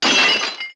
TP_Pot_Shatter3.wav